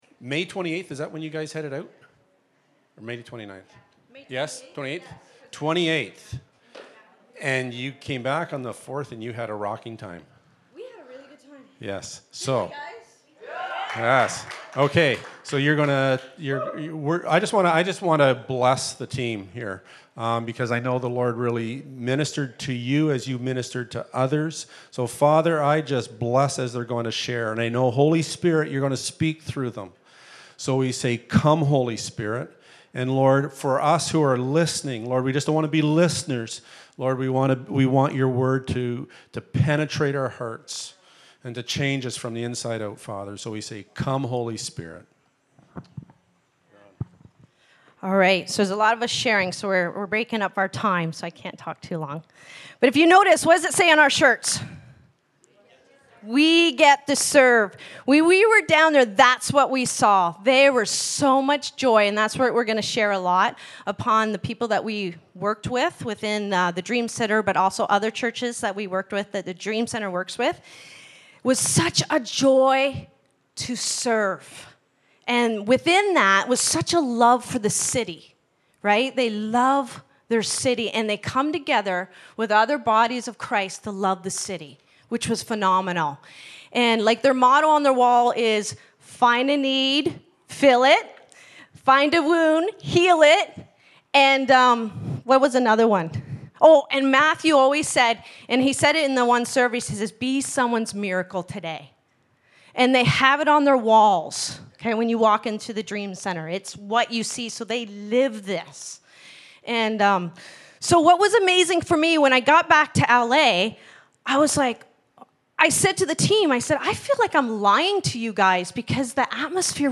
This morning the team shares stories and insights from this time together.
Service Type: Sunday Morning